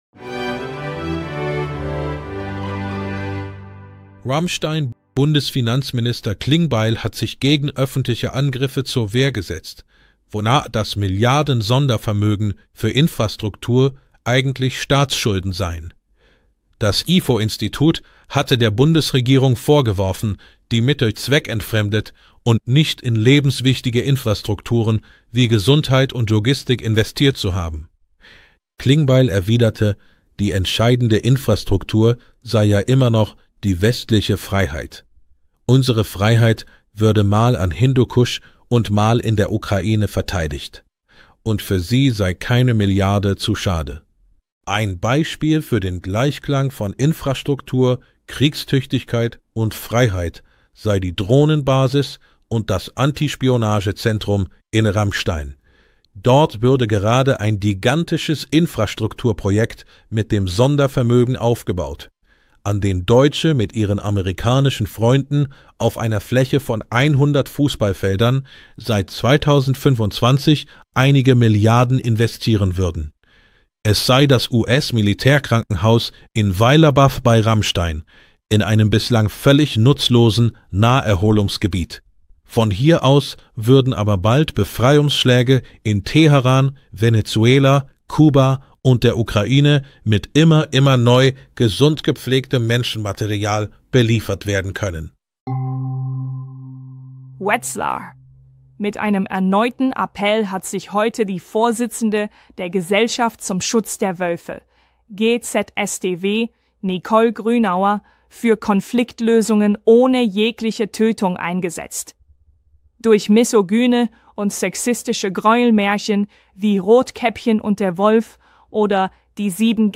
Beim gemeinsamen Talk mit der Th�ringer-Allgemeinen Zeitung diskutieren eine �rztin und ein Krankenkassenvertreter �ber die Patientenversorgung in Th�ringen.